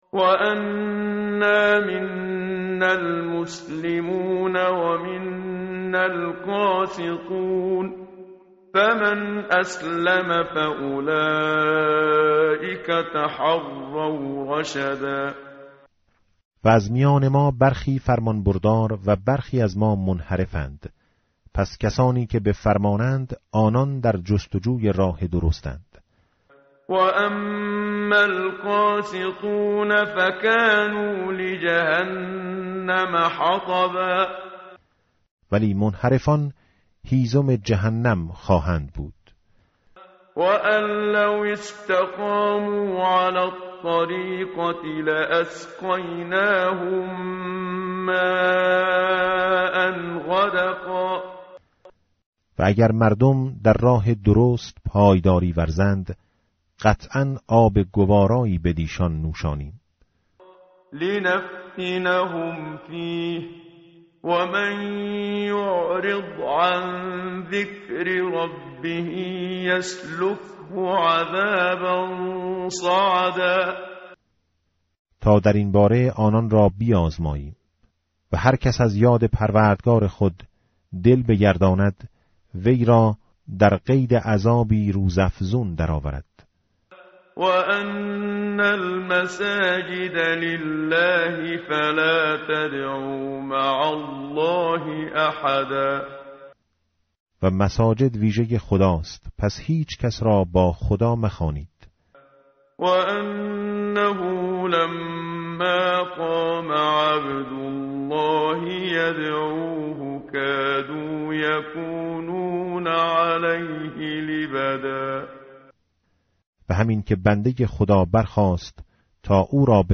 tartil_menshavi va tarjome_Page_573.mp3